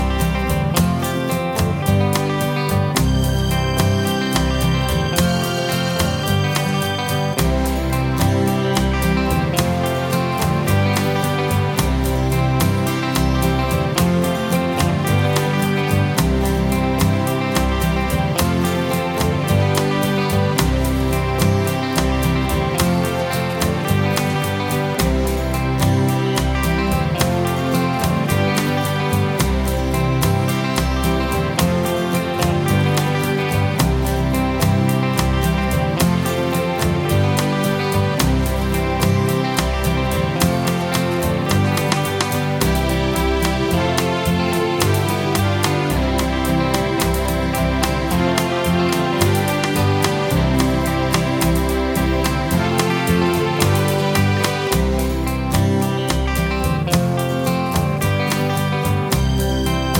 Rock 'n' Roll